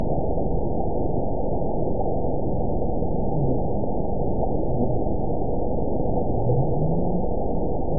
event 922100 date 12/26/24 time 11:00:53 GMT (11 months, 1 week ago) score 9.47 location TSS-AB04 detected by nrw target species NRW annotations +NRW Spectrogram: Frequency (kHz) vs. Time (s) audio not available .wav